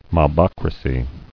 Mob`o*crat"ic , a. Of, or relating to, a mobocracy.